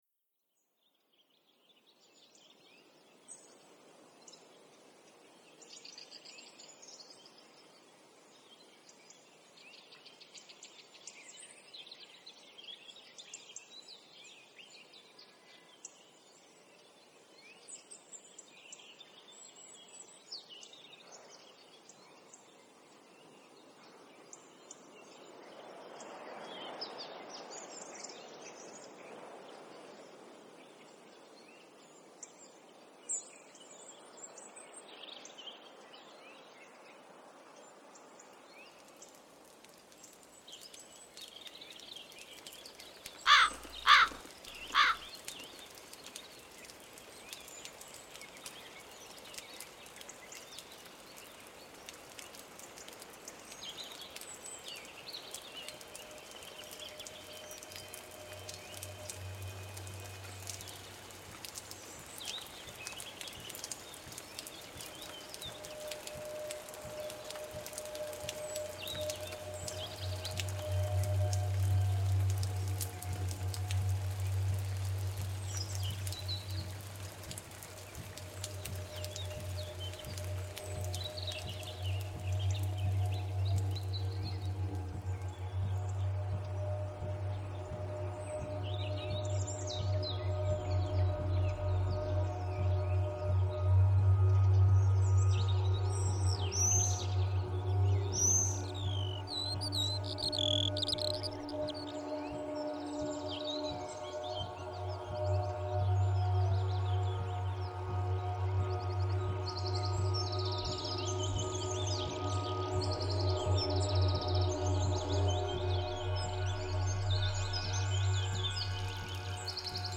Autumn_Ambiance
The Modular Active Adaptive Autumn Soundscape.
AutumnAmbiance.mp3